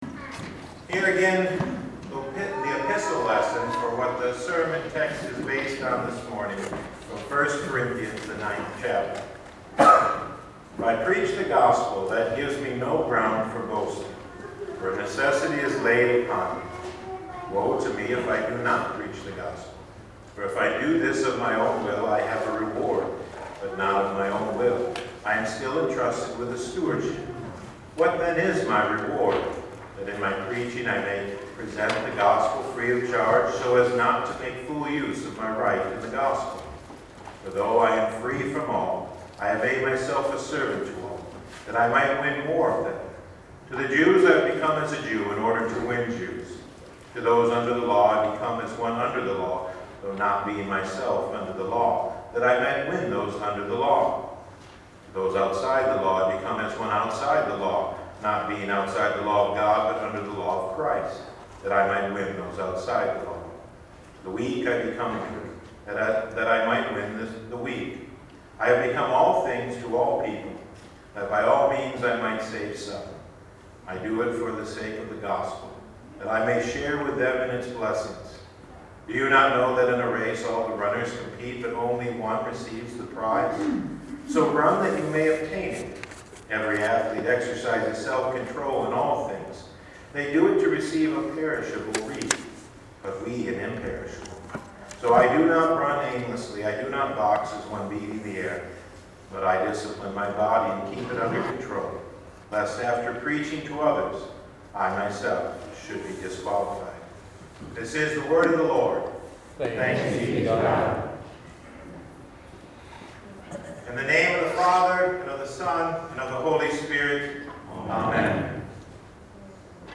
Past Sermons (Audio) - St. Luke Lutheran Church